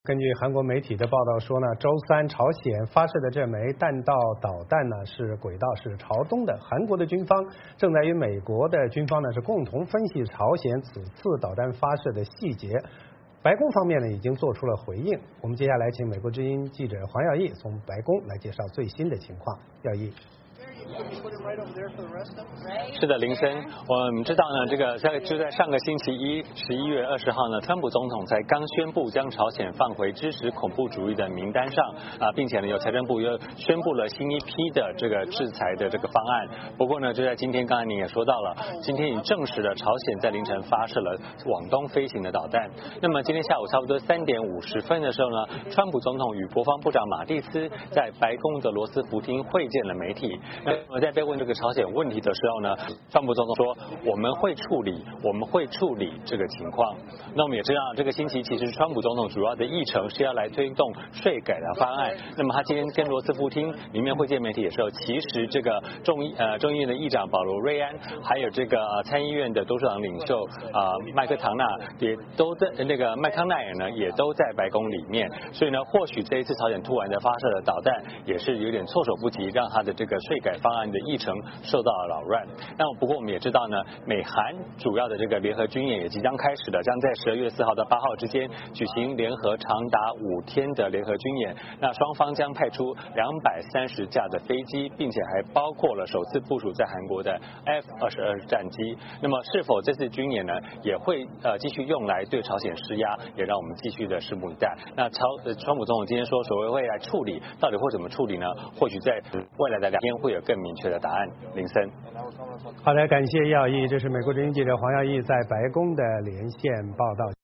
VOA连线： 白宫回应朝鲜再射导弹